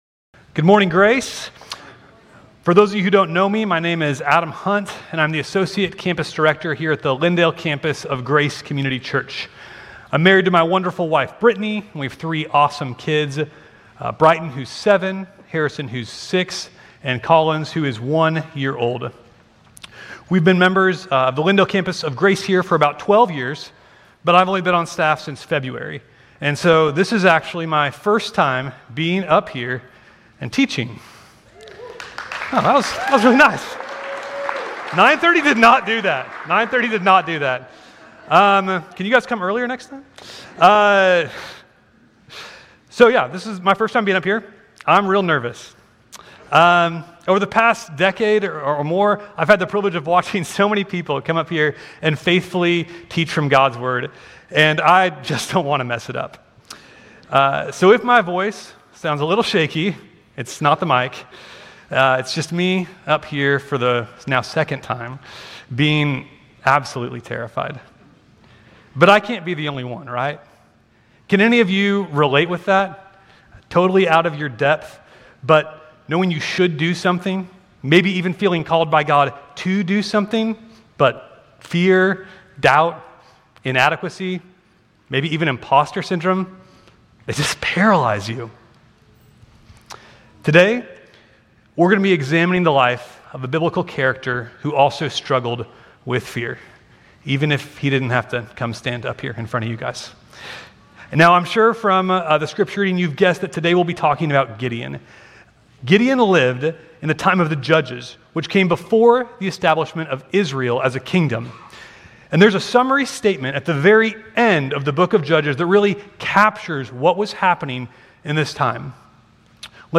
Grace Community Church Lindale Campus Sermons 7_20 Lindale Campus Jul 21 2025 | 00:27:10 1x 00:00 / 00:27:10 Subscribe Share RSS Feed Share Link Embed